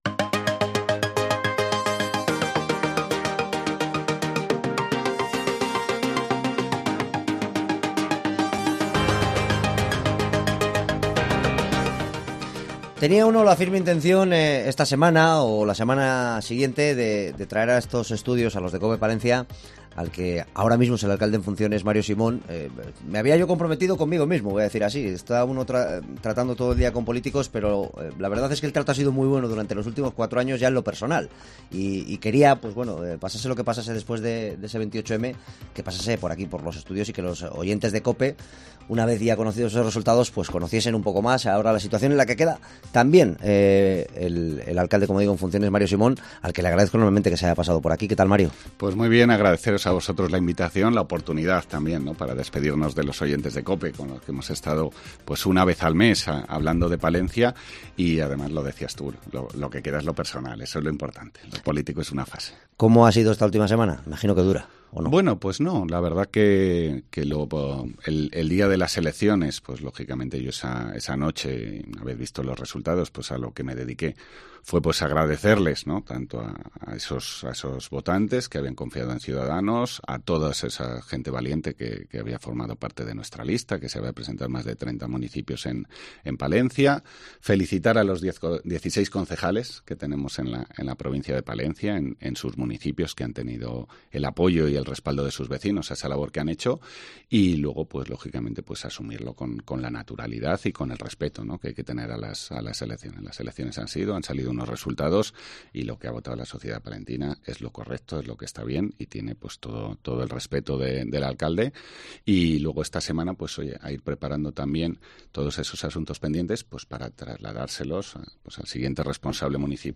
Entrevista a Mario Simón, alcalde de Palencia en funciones (08/06/2023)